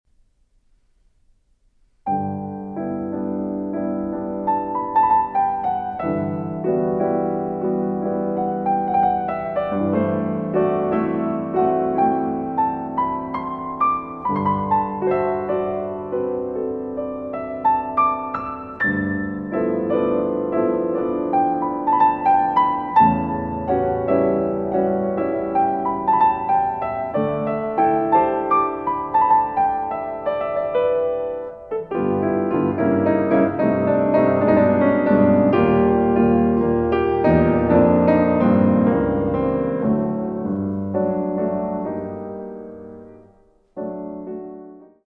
In tempo di una Serenetella